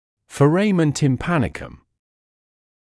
[fə’reɪmen tɪm»pænɪkəm][фэ’рэймэн тим’пэникэм]тимпаническое отверстие, барабанное отверстие, отверстие Хушке